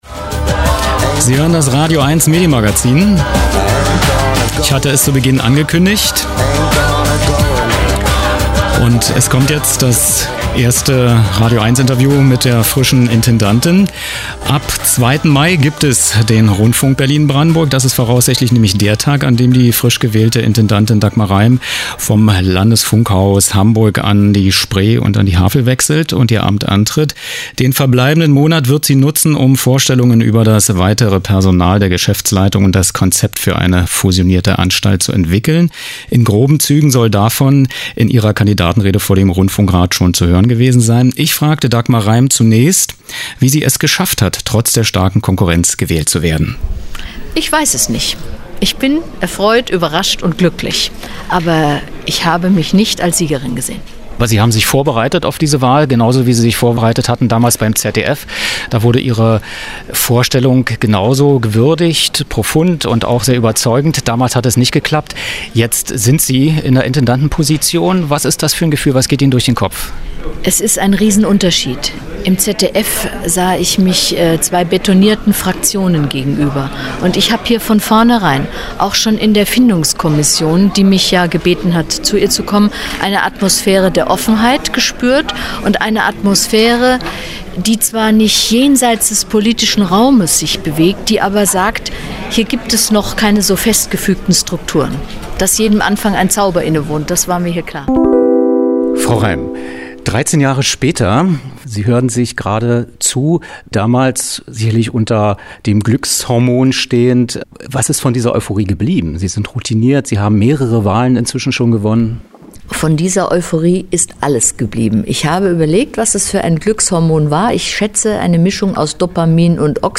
Erstes und letztes Interview für das radioeins-Medienmagazin